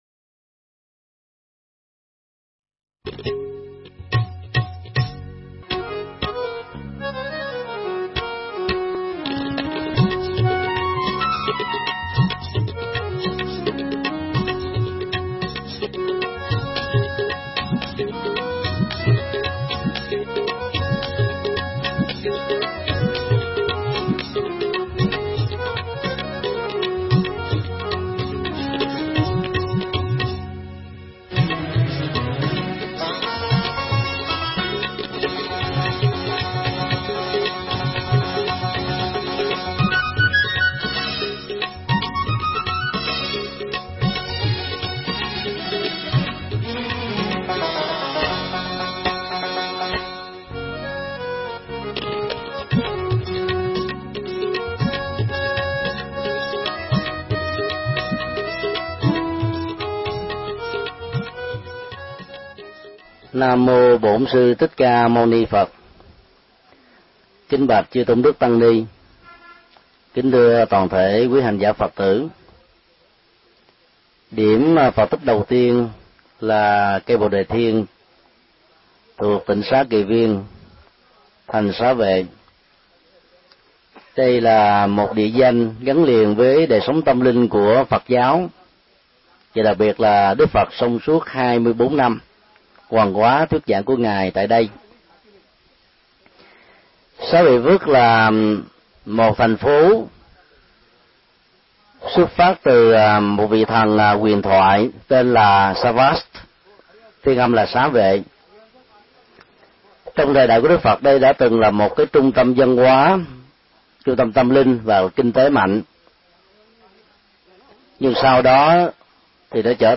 Tải mp3 Pháp thoại Phật Tích Ấn Độ 5: Thành Xá Vệ Và Tịnh Xá Kỳ Viên
giảng tại cây Bồ Đề ANAN (Ấn Độ)